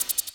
Shaker 06.wav